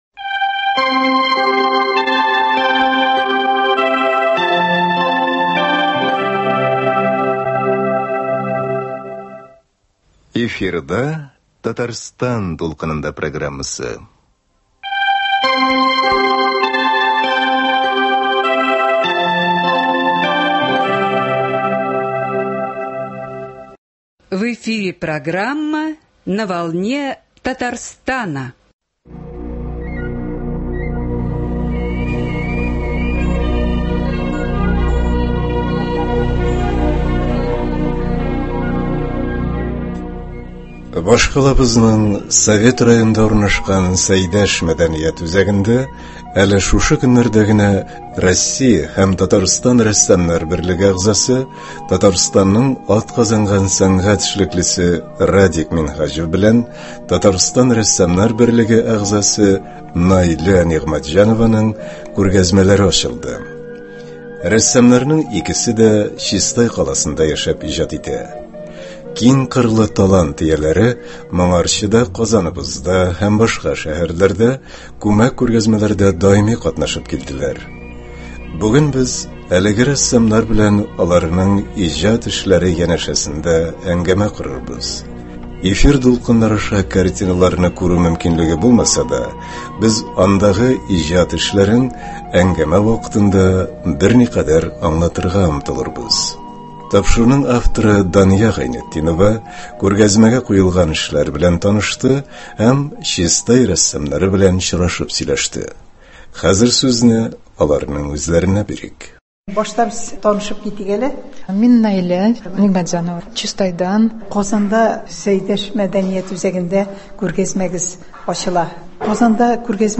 Әлеге тантанадан язма тәкъдим итәбез.